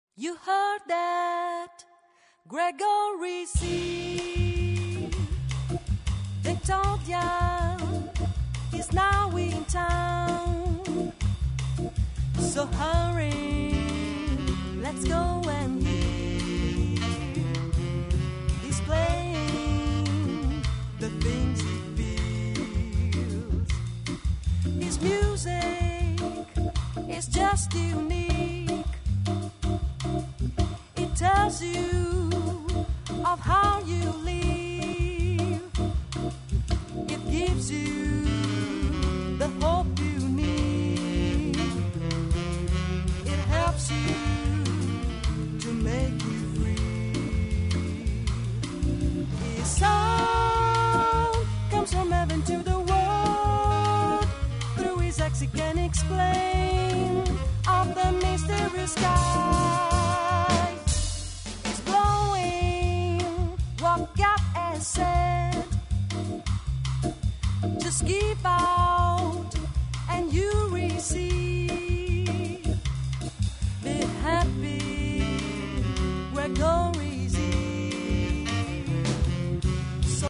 Voce
Batterie